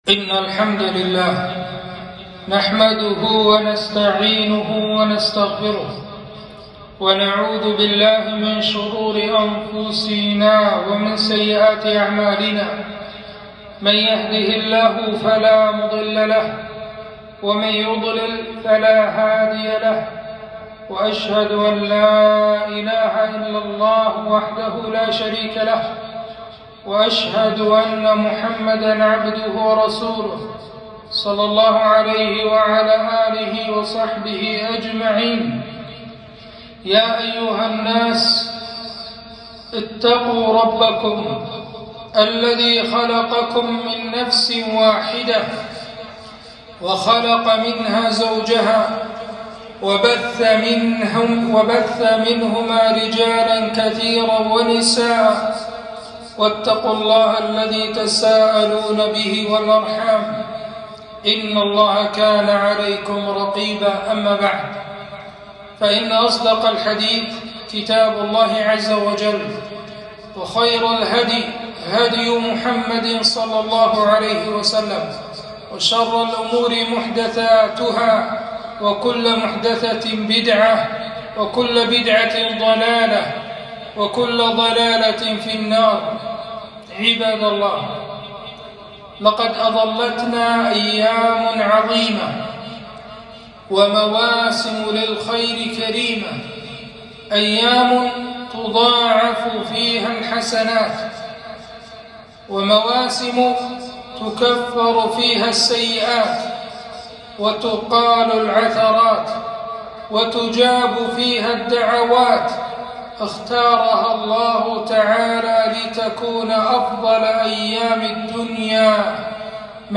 خطبة - عشر ذي الحجة فضلها واغتنامها